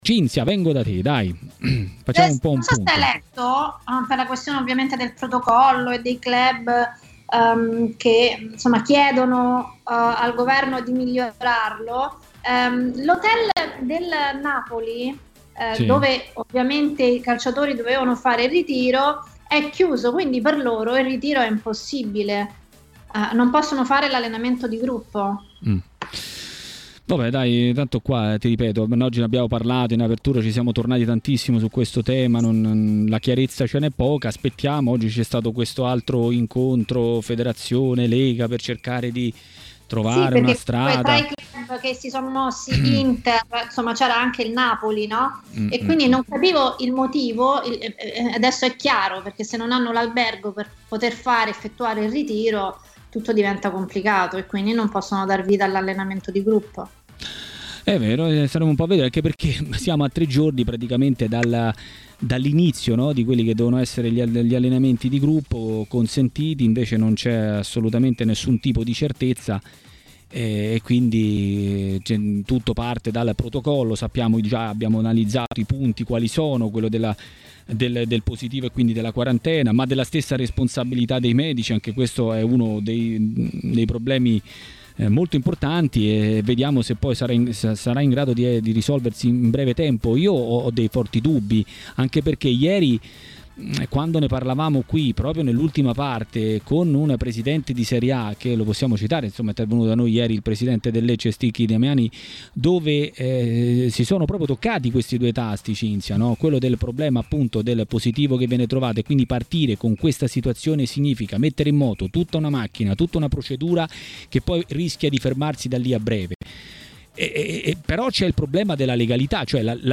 A Maracanà, nel pomeriggio di TMW Radio, è arrivato il momento del direttore Mario Sconcerti.